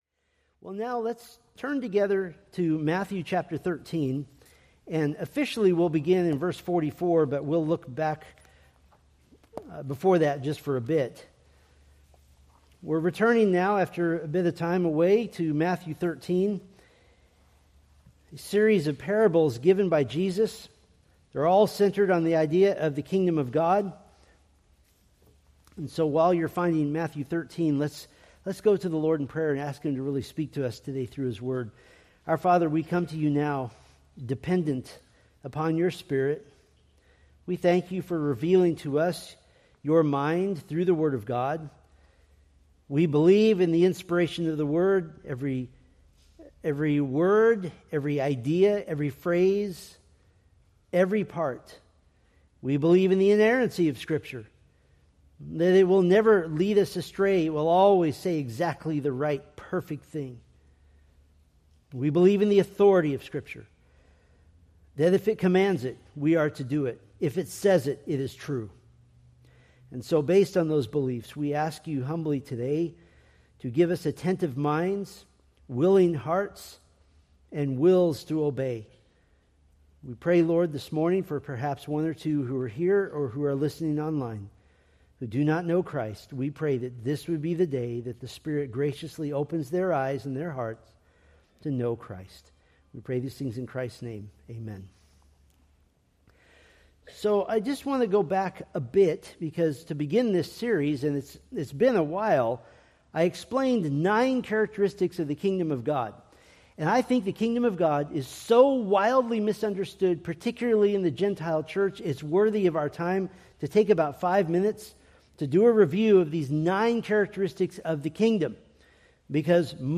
Preached March 22, 2026 from Matthew 13:44-46